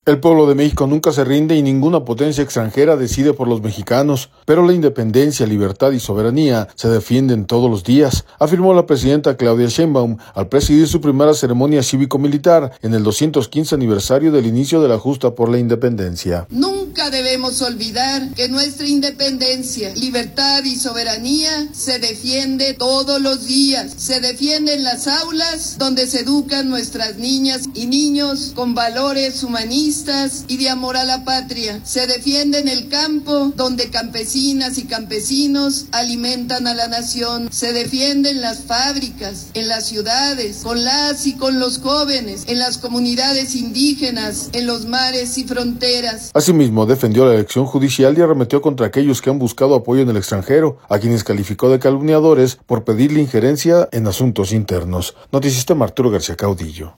El pueblo de México nunca se rinde y ninguna potencia extranjera decide por los mexicanos, pero la Independencia, Libertad y Soberanía se defienden todos los días, afirmó la presidenta Claudia Sheinbaum, al presidir su primera ceremonia cívico-militar en el 215 aniversario del inicio de la justa por la Independencia.